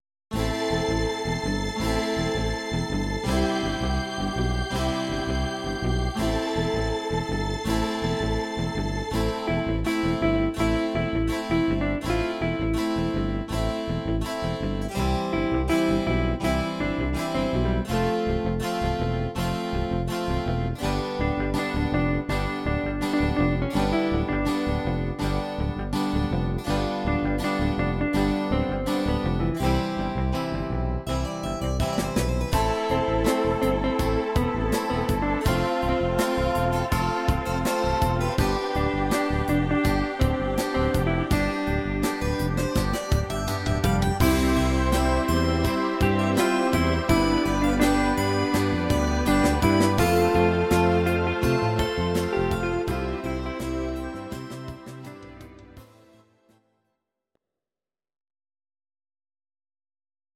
Audio Recordings based on Midi-files
Our Suggestions, Pop, Ital/French/Span, 1970s